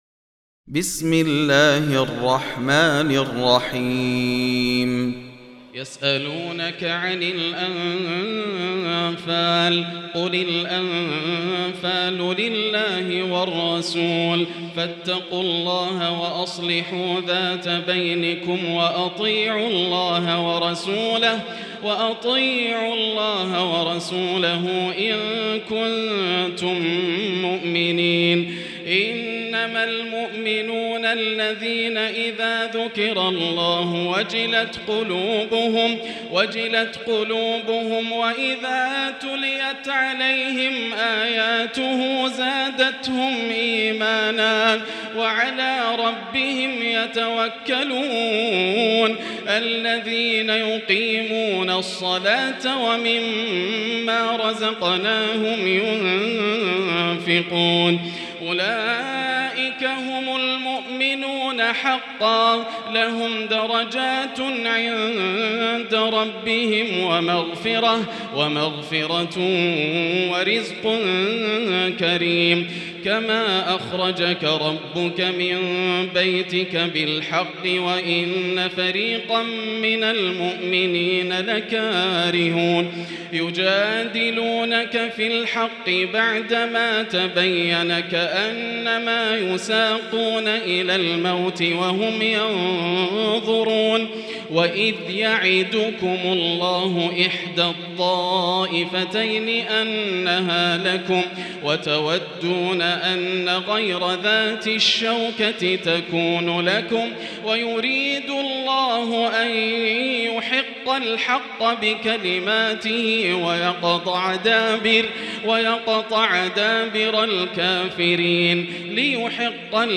المكان: المسجد الحرام الشيخ: فضيلة الشيخ ياسر الدوسري فضيلة الشيخ ياسر الدوسري الأنفال